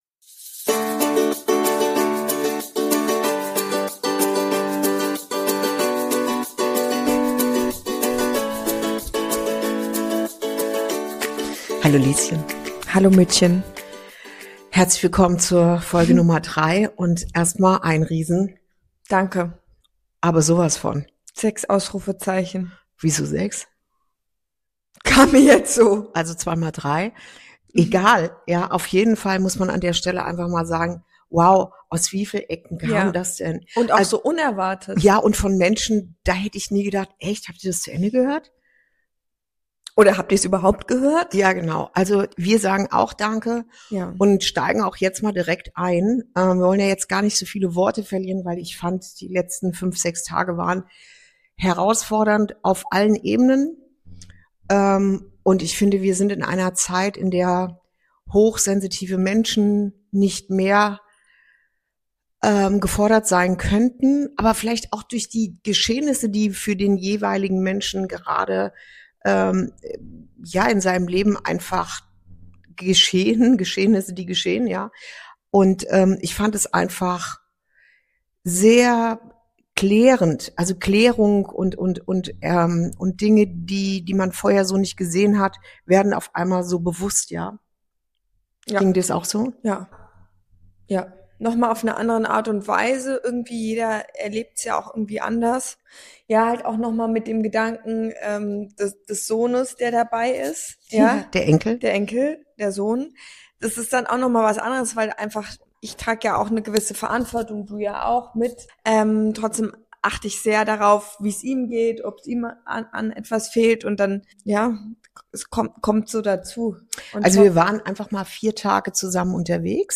Folge 3: Mitten im Wandel - Hochsensibel durch den Alltag navigieren ~ Inside Out - Ein Gespräch zwischen Mutter und Tochter Podcast
Ehrlich, direkt und mit einer guten Portion Humor erzählen die beiden von einer ereignisreichen Woche – inklusive chaotischer Reiseabenteuer, tiefer Reflexionen und der Kunst, in turbulenten Zeiten gelassen zu bleiben.